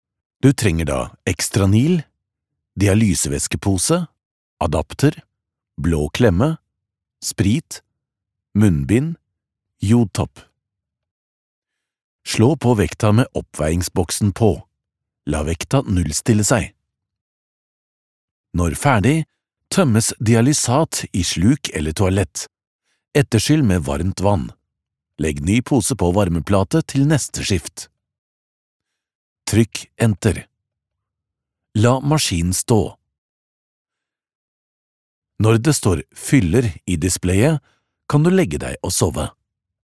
Vídeos explicativos
Adulto joven
Mediana edad
CálidoAutoritarioCreíblePotente